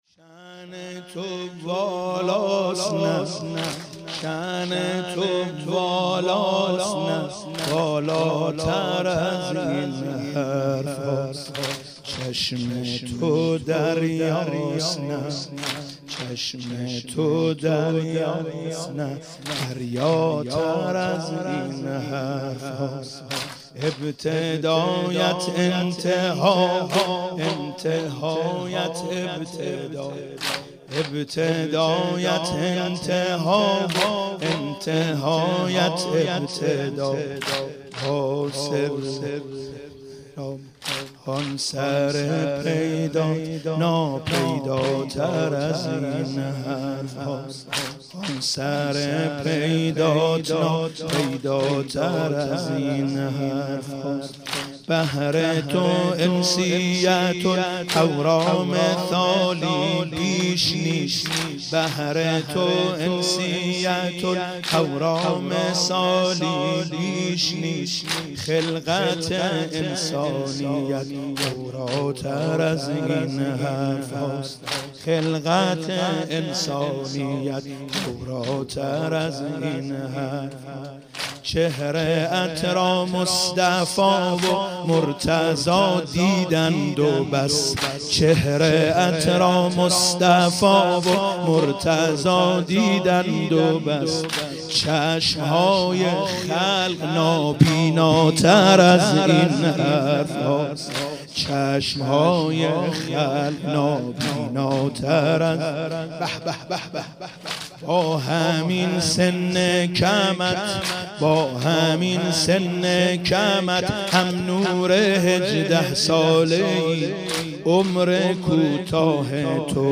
فاطمیه 96 - هیئت آل یاسین - واحد - شان تو والاست بالاتر